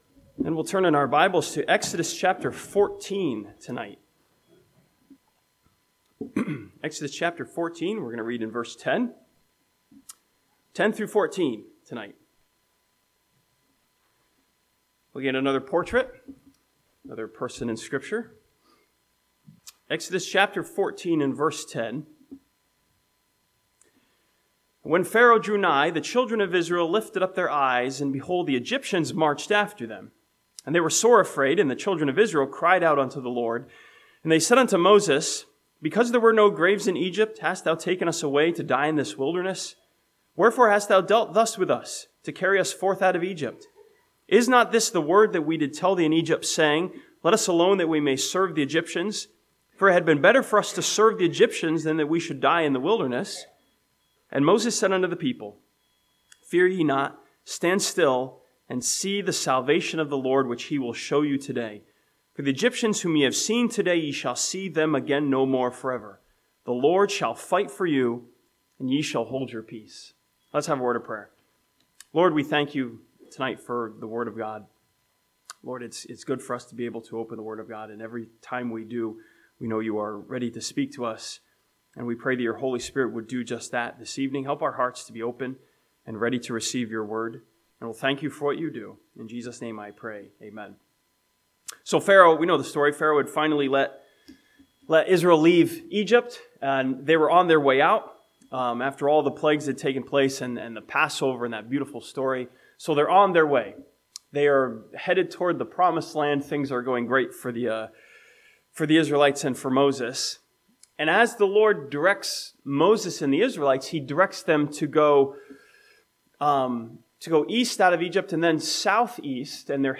This sermon from Exodus chapter 14 looks at Moses as a portrait of security as he stands before Israel confidently.